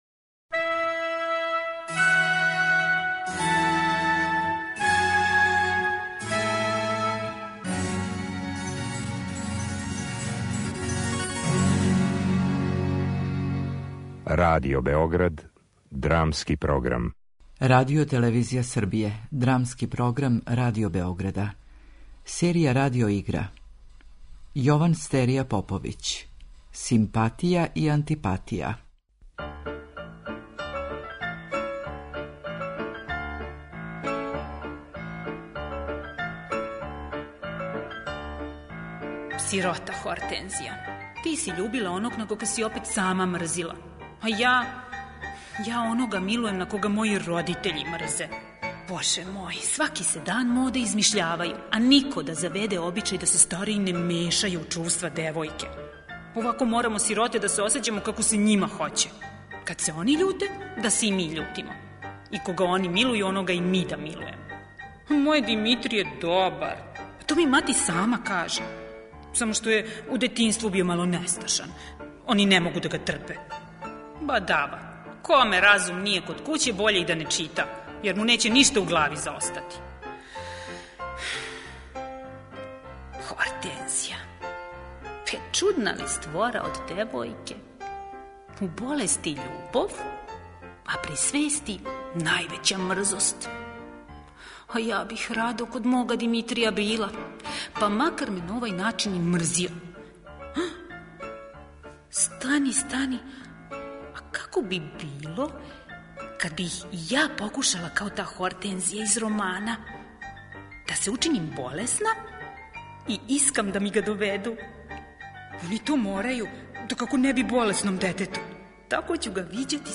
Радио игра
Радијска адаптација овог комада истиче њене потенцијале који је приближавају поетици апсурда и надреализму.